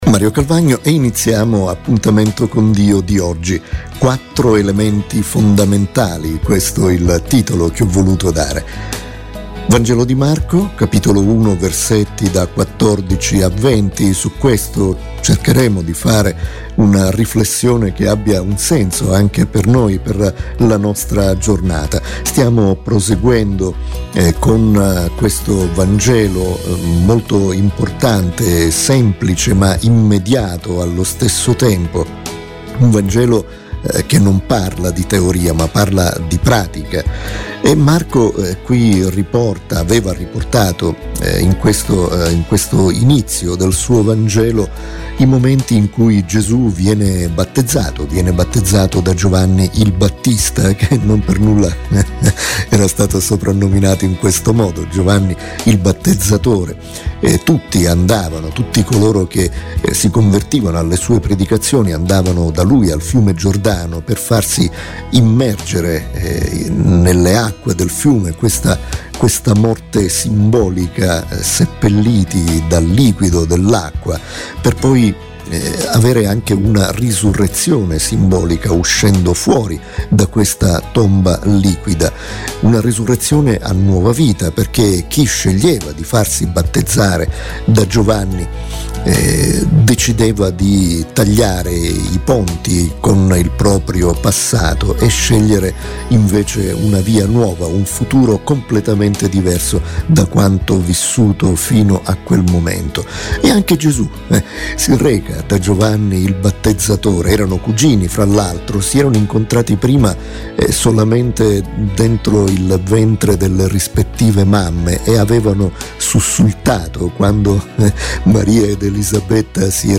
Riflessione spirituale